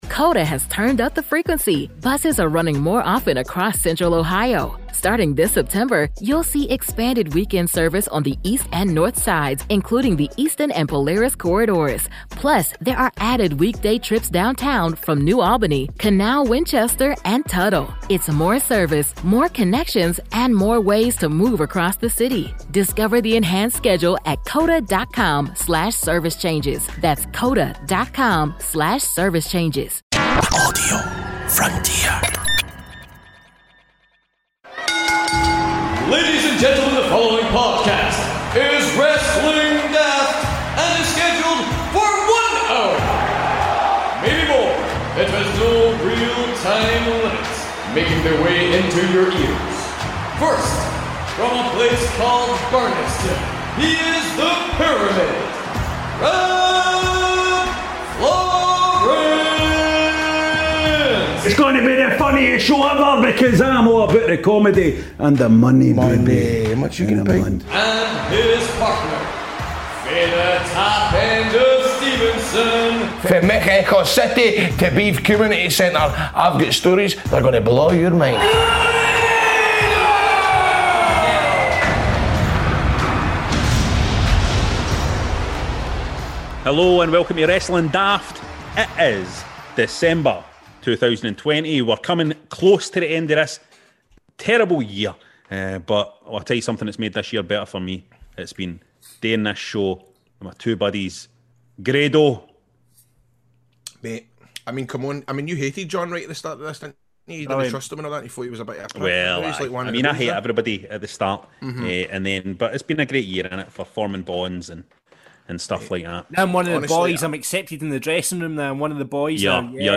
It's the last live show of the year, so we thought we would commemorate that with the inaugural 'Run In Rumble'.